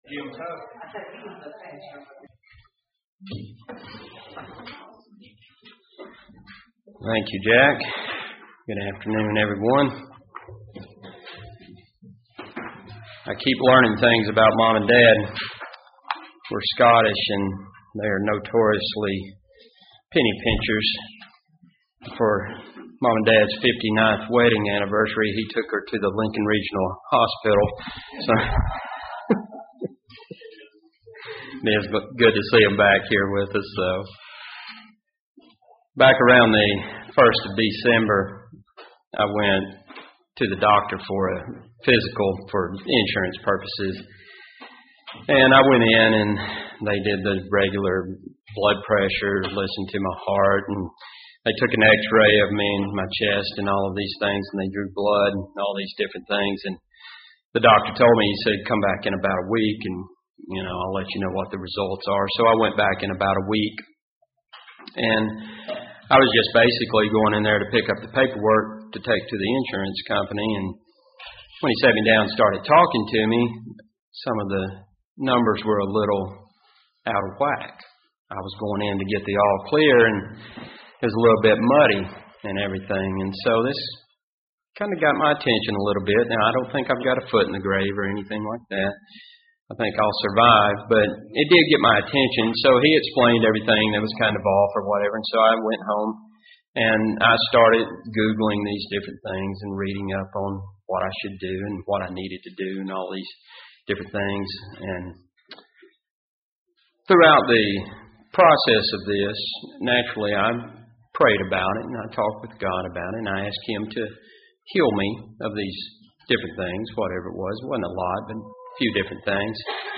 There are times when examining physical health can lead us to spiritual discoveries. This sermon looks at spiritual toxins and the need to detoxify spiritually.
Given in Huntsville, AL